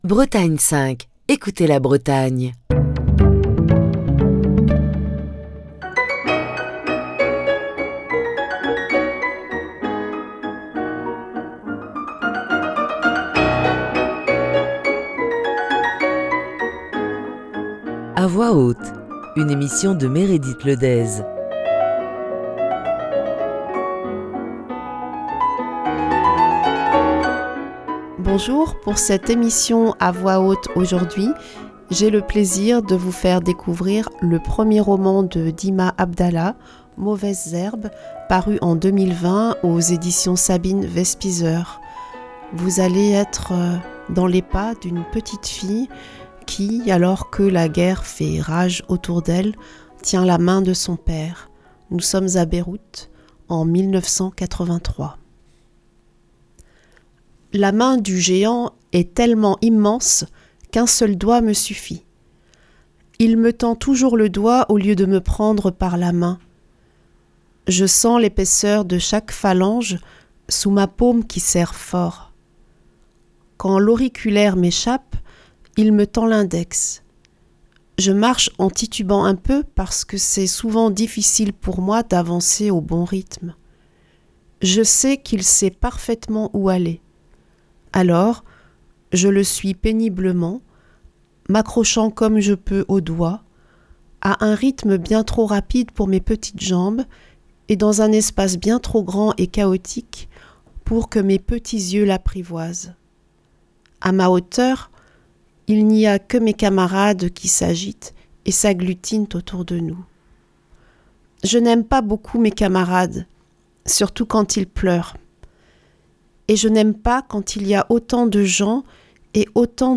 Émission du 8 octobre 2022.